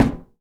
metal_tin_impacts_soft_05.wav